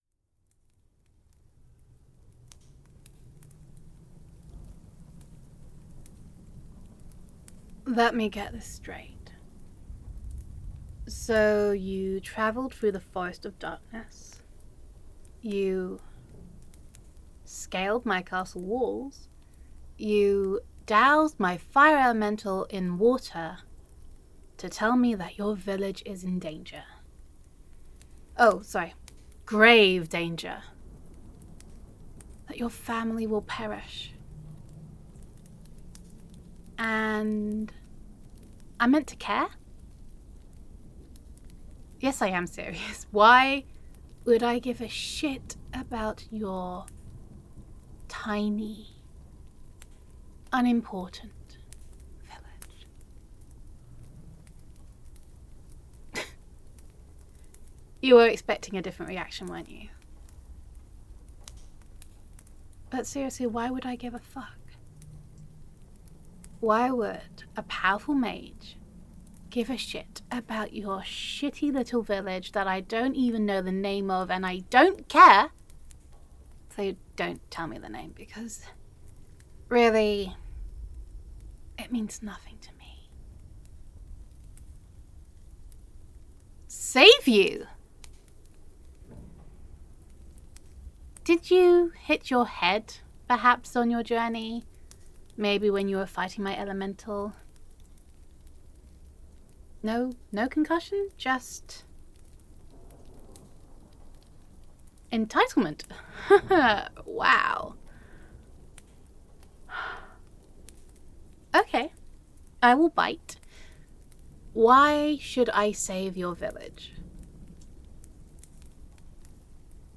[F4A] Villainous
[Fantasy Roleplay]